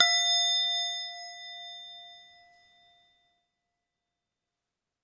Goblet_F_Loud
bell chime ding dong goblet instrument ping sound effect free sound royalty free Music